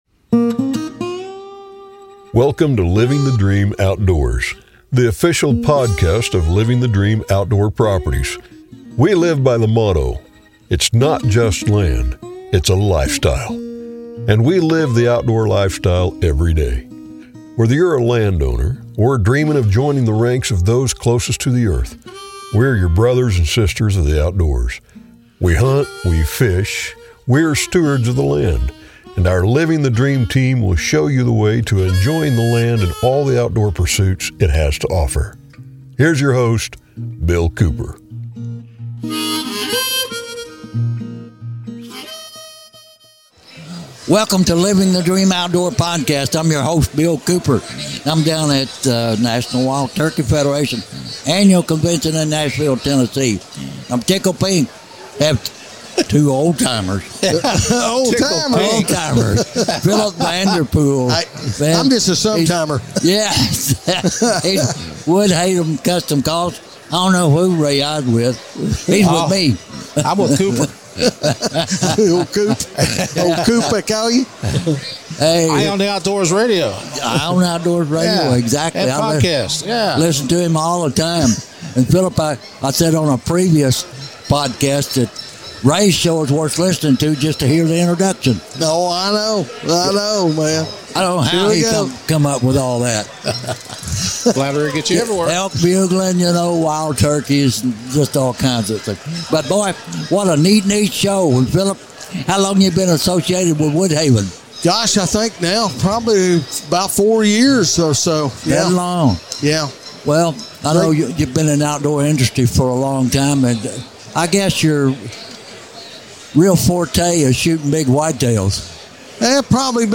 If you enjoy a lot of laughter and cutting up, as well as conservation history and everything turkey and turkey hunting, then this is the show for you.